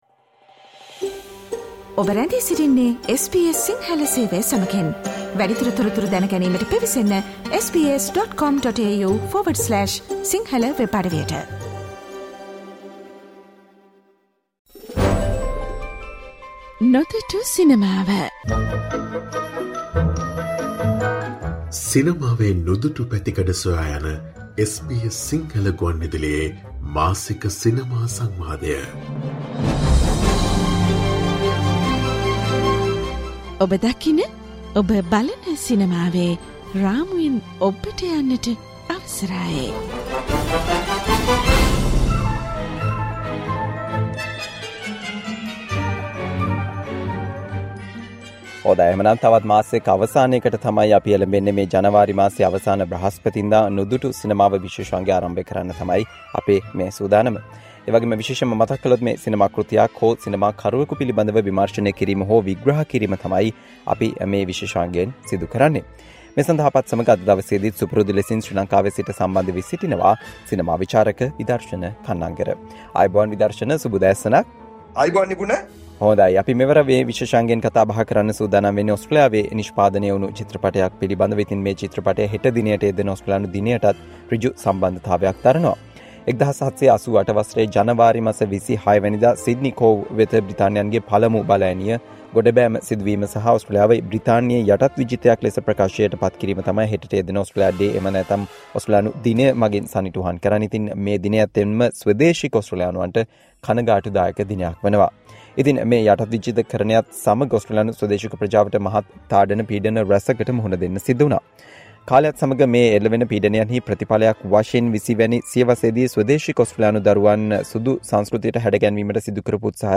SBS සිංහල ගුවන් විදුලියේ මාසික සිනමා විශේෂාංගය වන "නොදුටු සිනමාවෙන්" මෙවර "Australian Movie" ඕස්ට්‍රේලියානු චිත්‍රපටය පිළිබඳ කතා බහක්.